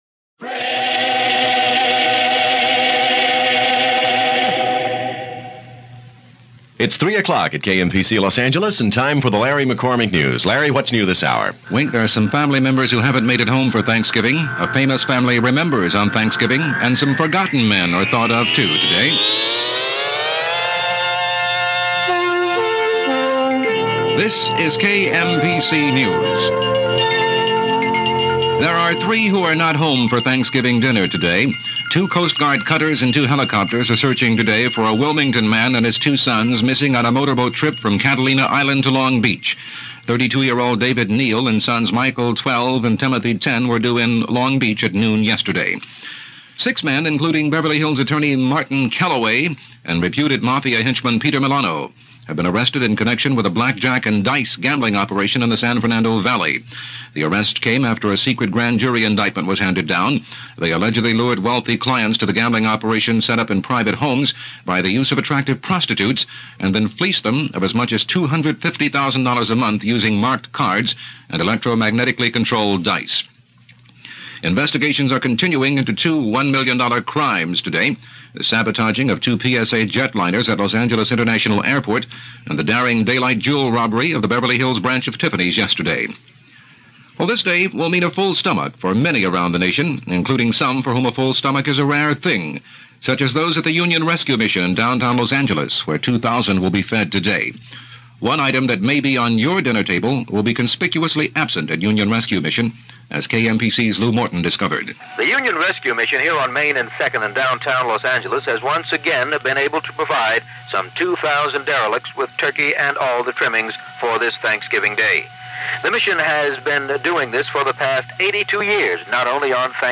His polished baritone could sell a product, spoof a genre, or summon a superhero, all with the same effortless charm. Whether cupping his ear in mock-seriousness or narrating a cartoon with deadpan flair, Owens turned the act of speaking into a performance art.
He wasn’t just a broadcaster—he was a sonic stylist, blending elegance with eccentricity, sincerity with satire.